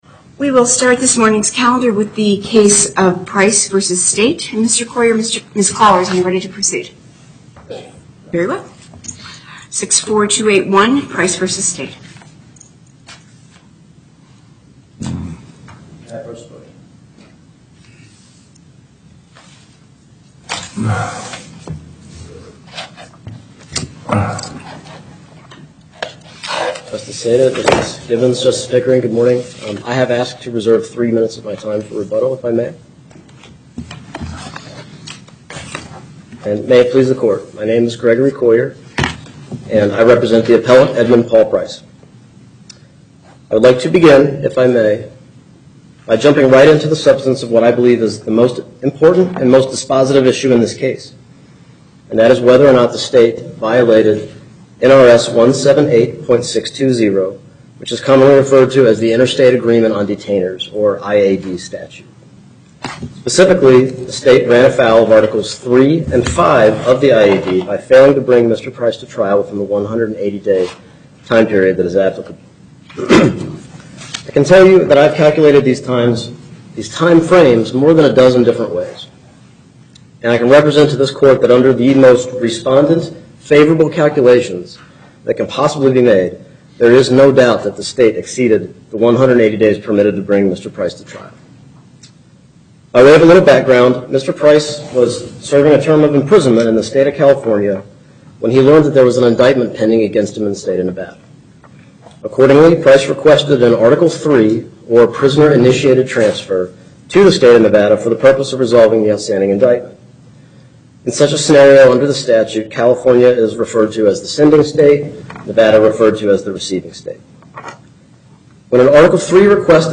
Location: Carson City Before the Northern Nevada Panel, Justice Saitta presiding